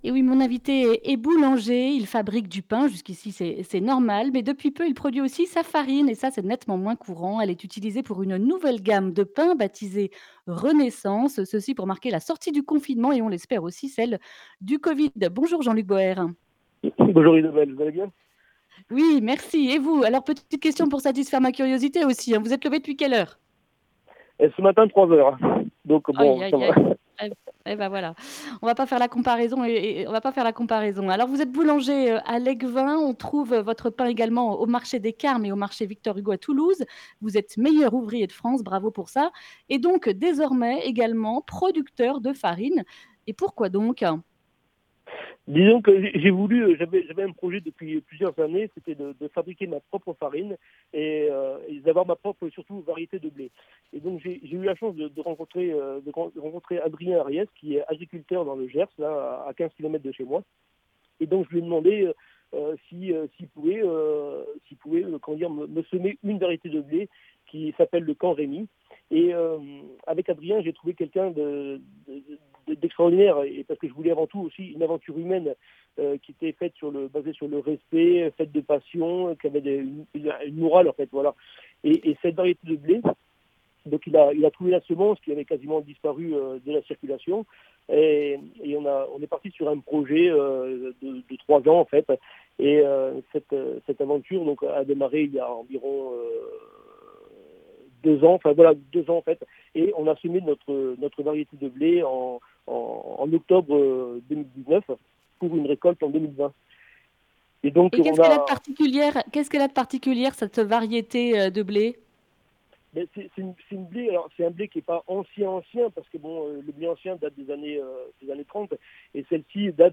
mardi 8 décembre 2020 Le grand entretien Durée 10 min